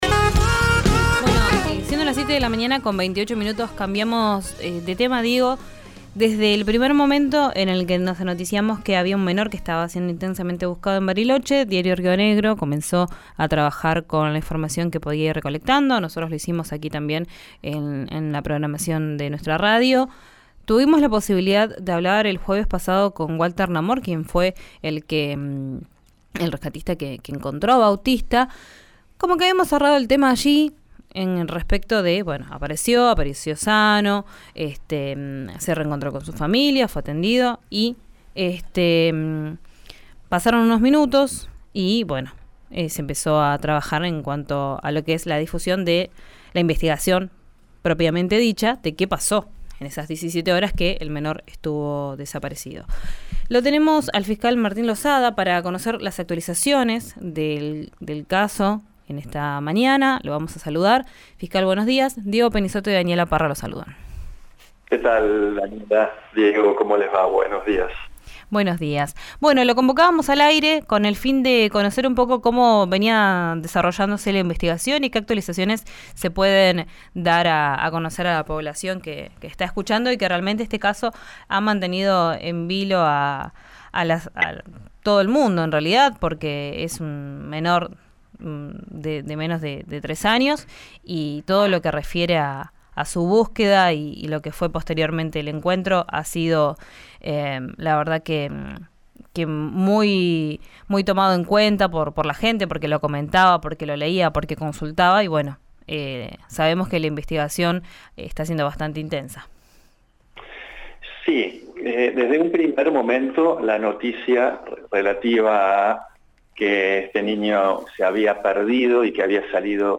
Escuchá al fiscal Martín Lozada, en RADIO RÍO NEGRO: